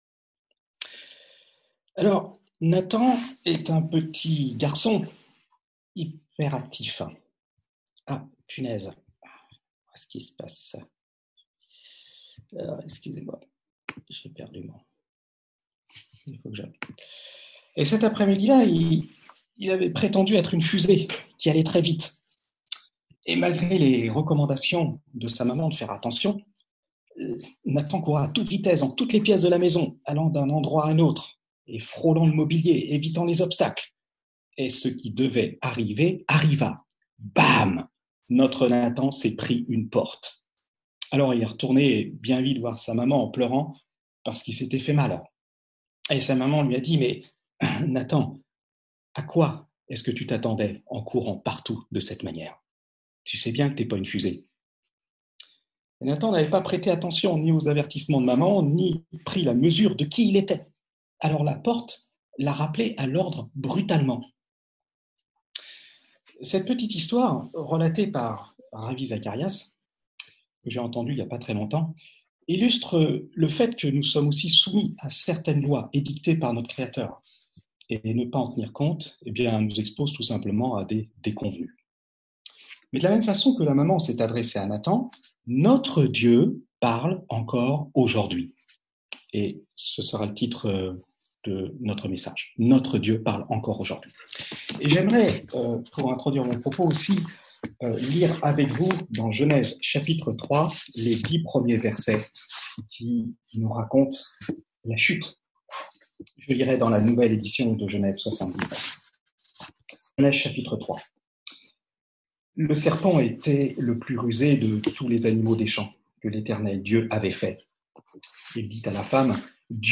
Culte Zoom – Eglise Protestante Evangélique Lyon 8°